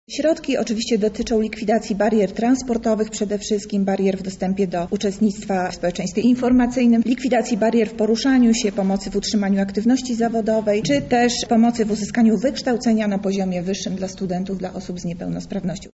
„Jeżeli będą one odpowiadały kryteriom i będą złożone prawidłowo, jest właściwie stu procentowa szansa, że będziemy mogli w pełni pokryć potrzeby osób z niepełnosprawnością” – mówi zastępca prezydenta Lublina Monika Lipińska